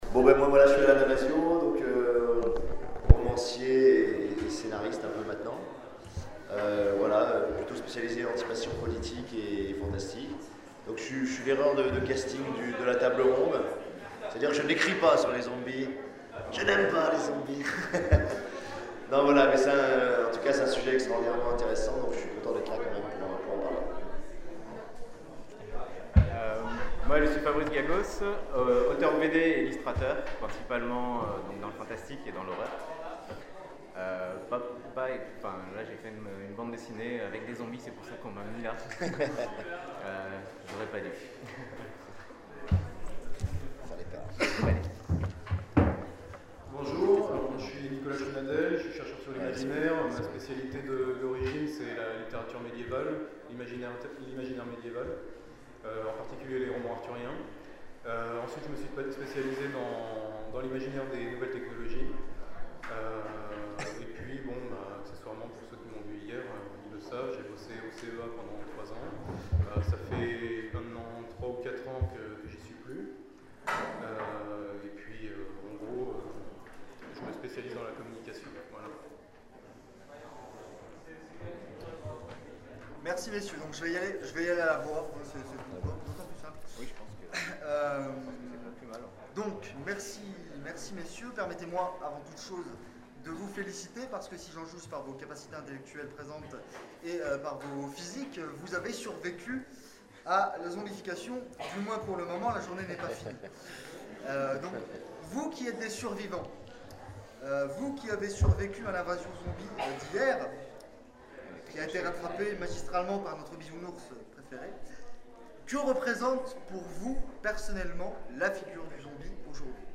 Conférence Rêves d'Ailleurs, Fig 2012 : Invasion, le zombie comme figure de l’Autre